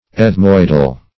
Ethmoid \Eth"moid\, Ethmoidal \Eth*moid"al\, a. [Gr.